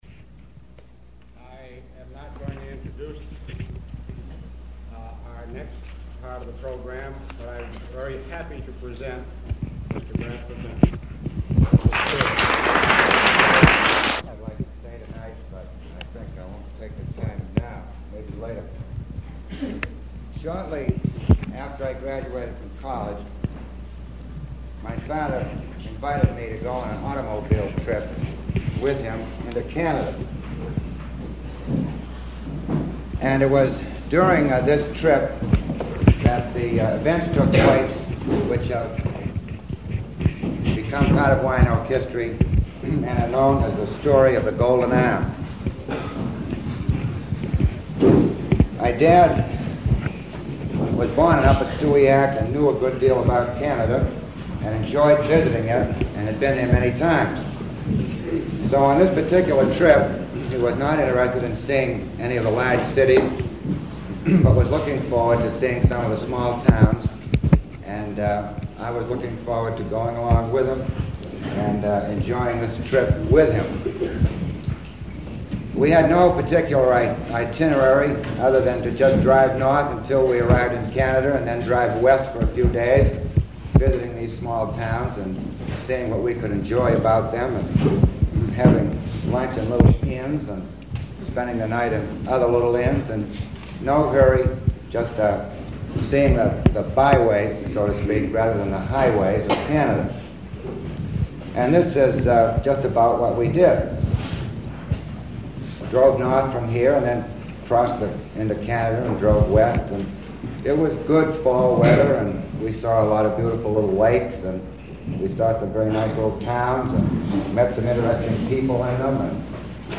The campfire was held indoors, and fortunately so because we'd never have gotten this gem fragment of Wyanoke History: Click